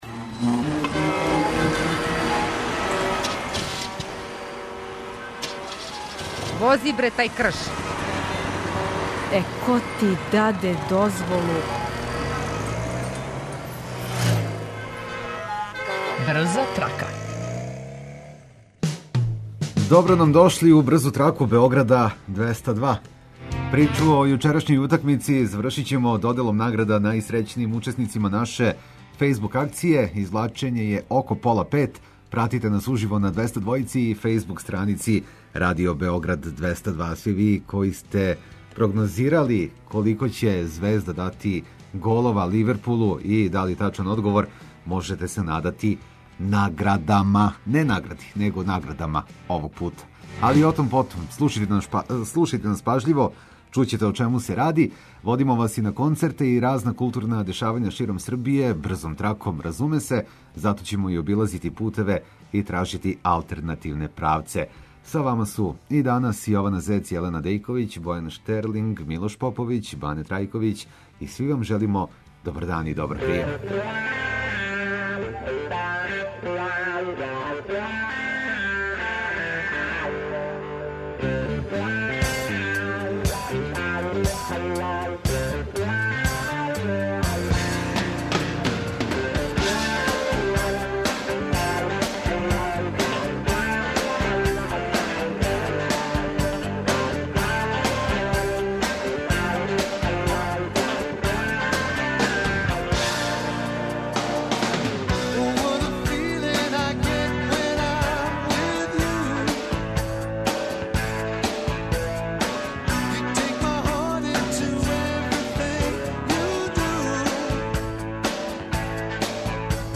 У „Брзој траци”, после 16 часова пратите уживо извлачење имена добитника награда.
Слушаоци репортери јављају новости из свог краја, па нам се јавите и ви.